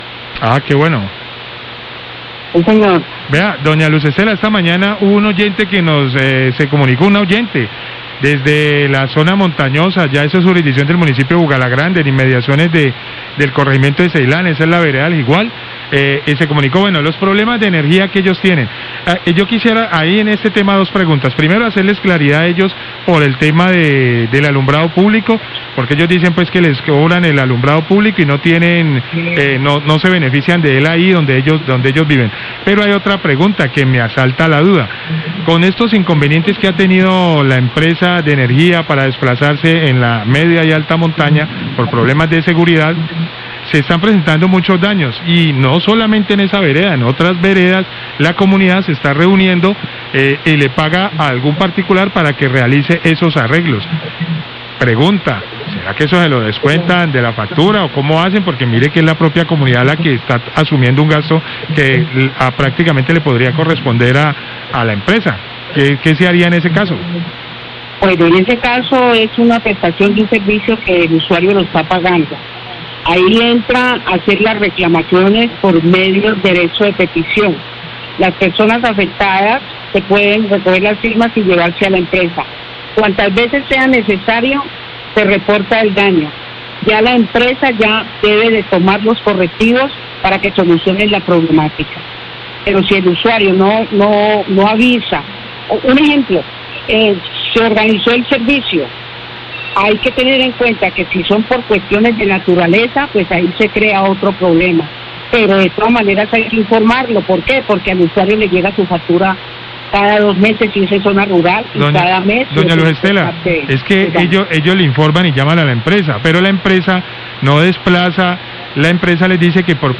Vocal de control de servicios públicos responde dudas de los oyentes, La Cariñosa, 1215pm
Radio